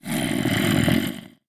zombie1.wav